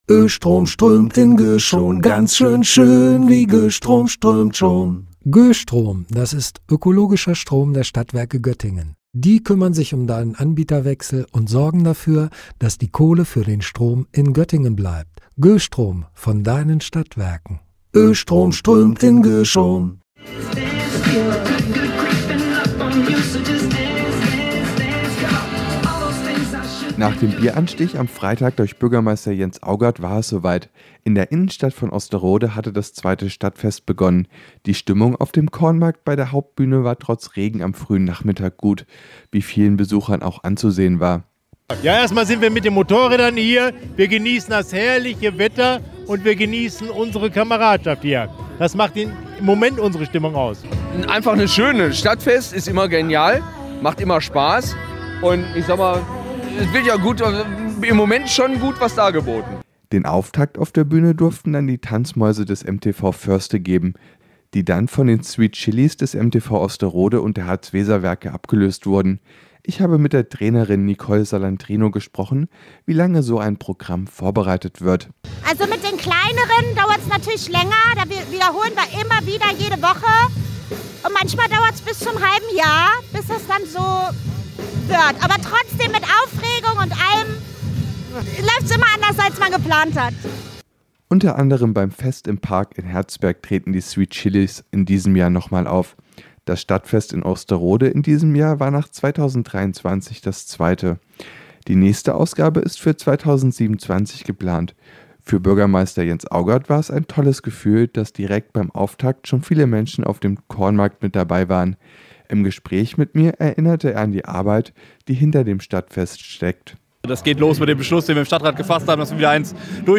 Beiträge > Tanzgruppen, Musik und Besucher aus der Region: Auftakt beim Stadtfest in Osterode - StadtRadio Göttingen
Natürlich mit viel Musik.